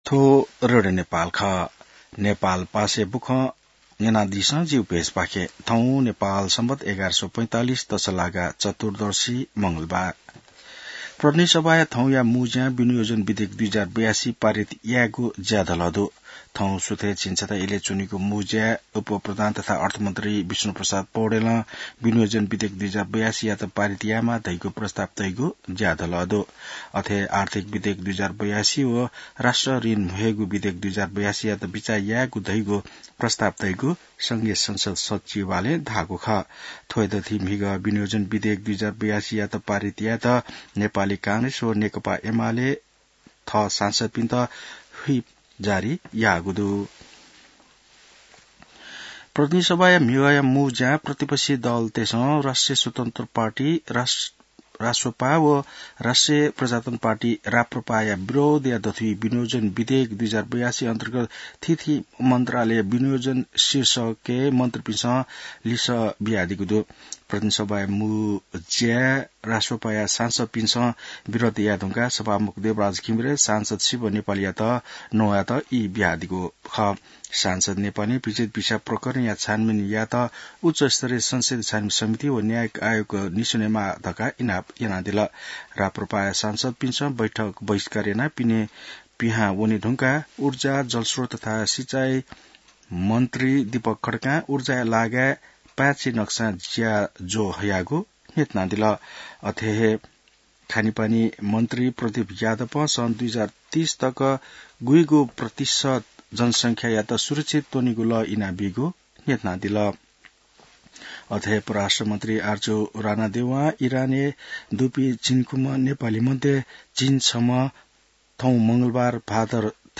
नेपाल भाषामा समाचार : १० असार , २०८२